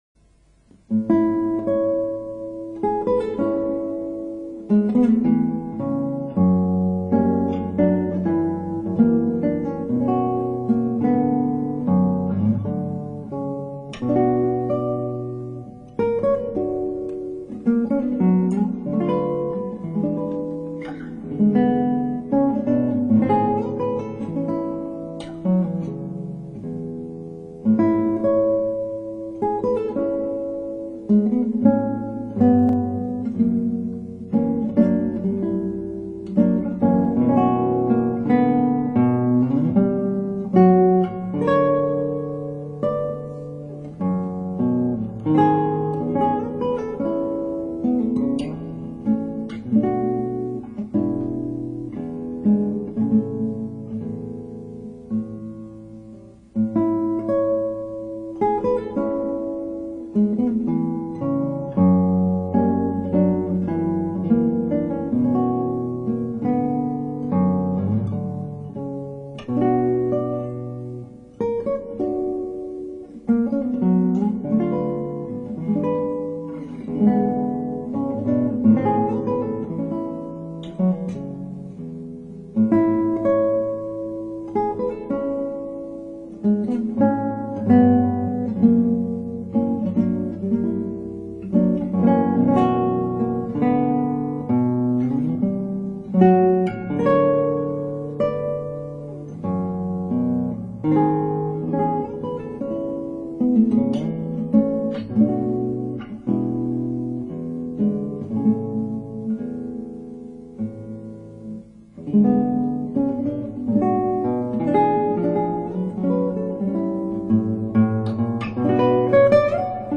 クラシックギター　ストリーミング　コンサート
さらにさらに、眠い曲調です。美しい曲なのですが、まるで子守唄のようです。
どうもこの頃の録音、エコー効き過ぎです。